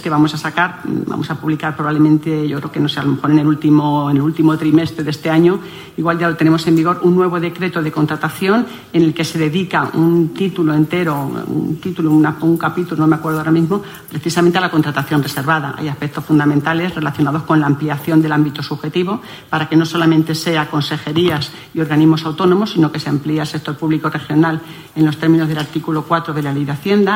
Consejería de Hacienda, Administraciones Públicas y Transformación Digital Lunes, 24 Marzo 2025 - 12:30pm Durante la inauguración de la jornada sobre compra pública responsable ‘Mecanismos legales y buenas prácticas para la inclusión sociolaboral’, organizada por FEACEM-CLM, Sáiz ha avanzado que para el último trimestre del año, según se estima, se va a publicar desde esta Consejería un nuevo Decreto de Contratación Pública al que se va dedicar, por primera vez, un capítulo dedicado en exclusiva a la contratación reservada; recogiendo aspectos fundamentales relacionados con la ampliación del ámbito subjetivo para que no solo sean Consejerías y organismos autónomos, sino que se amplíen al Sector Público Regional en los términos del artículo 4 de la Ley de Hacienda. macarena_saiz_trabajando_en_nuevo_decreto_contratacion.mp3 Descargar: Descargar